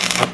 welderstop.wav